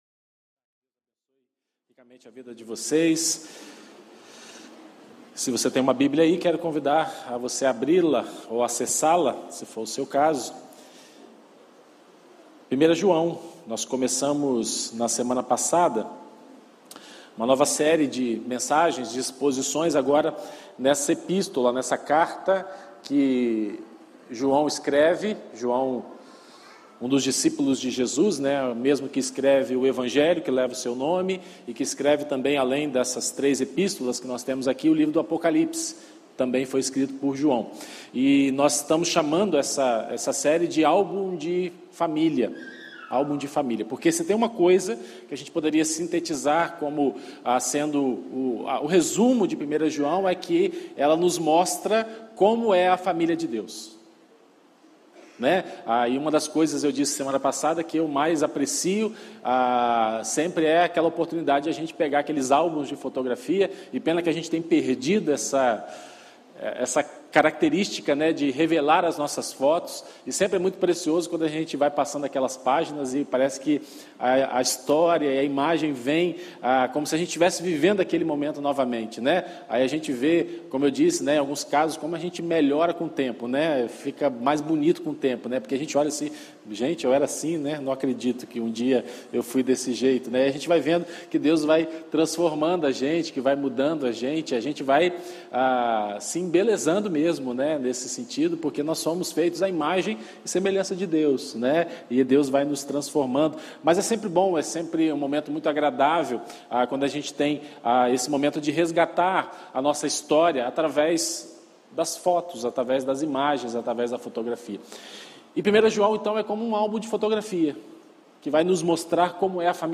Exposições em 1º João